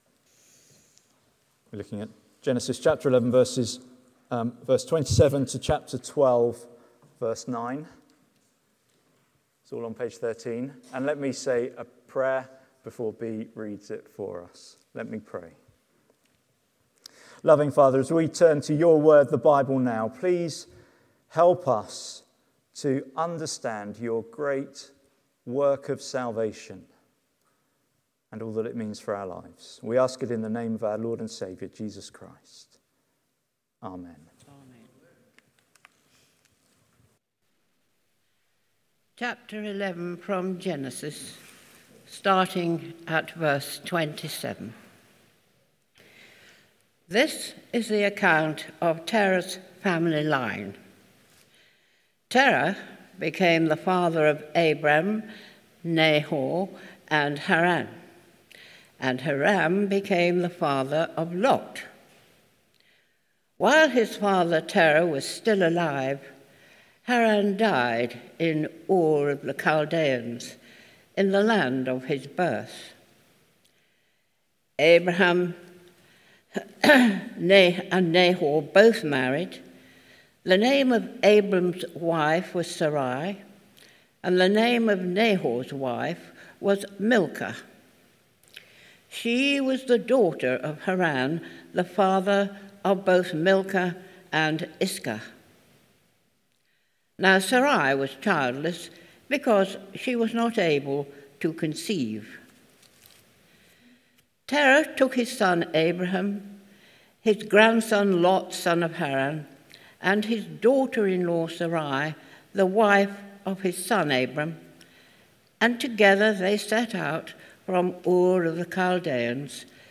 Sermon Transcript Study Questions